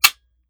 7Mag Bolt Action Rifle - Dry Trigger 002.wav